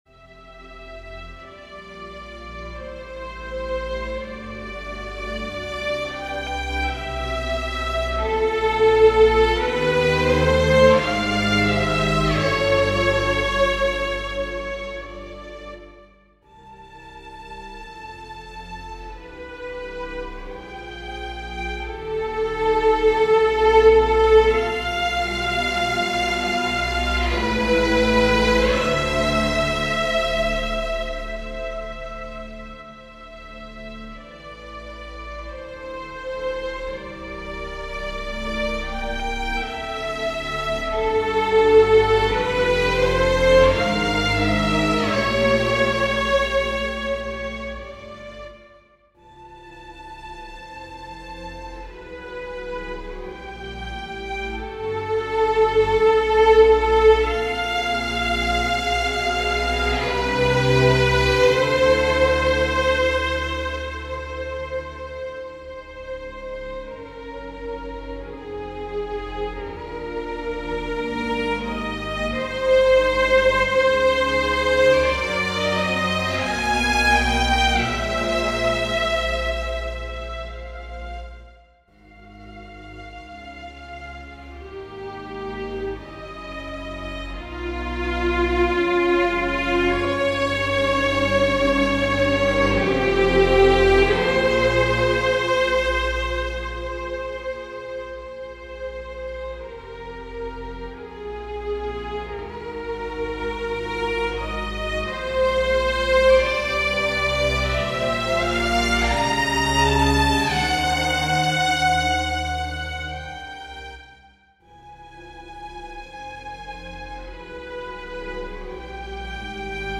Persichetti Exercise 5 - 18 for Chamber Orchestra